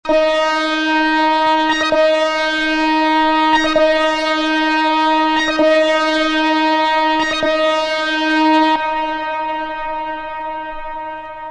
Detroit techno classic